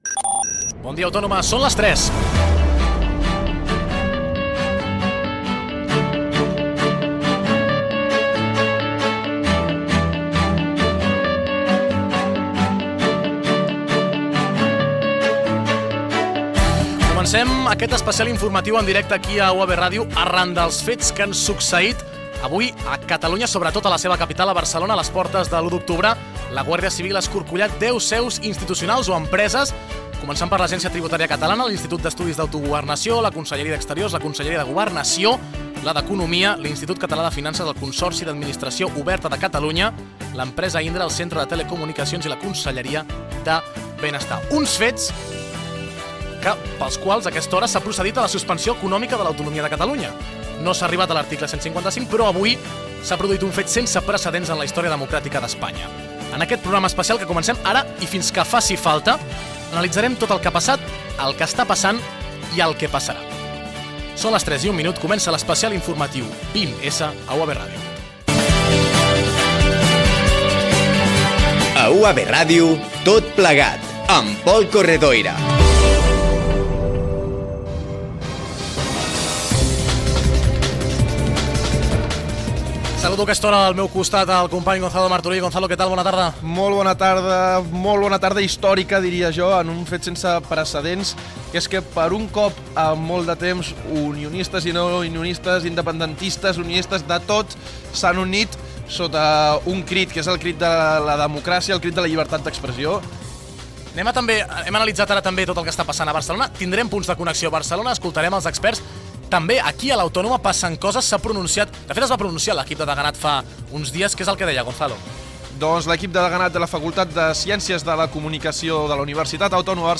Gènere radiofònic Informatiu
Banda FM